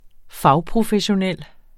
Udtale [ ˈfɑw- ]